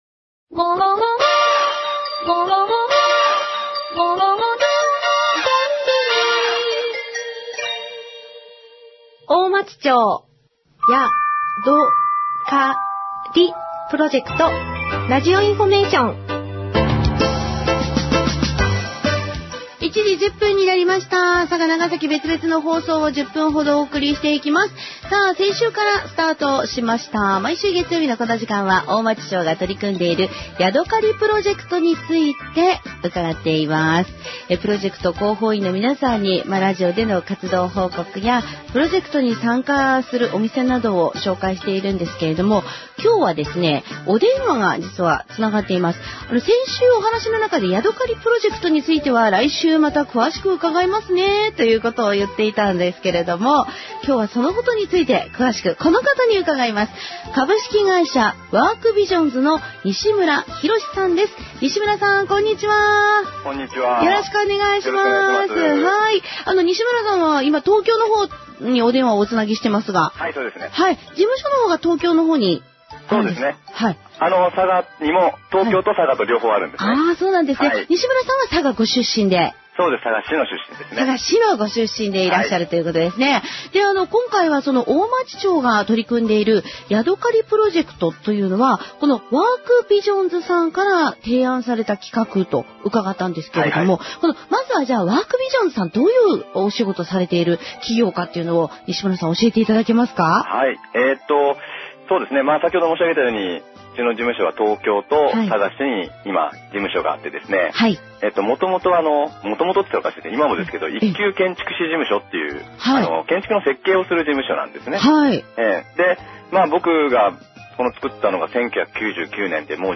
このページでは、平成27年1月26日放送から平成27年6月29日の期間、NBCラジオ佐賀で放送された「大町町やどかりプロジェクトラジオインフォメーション」の番組内容をご紹介します。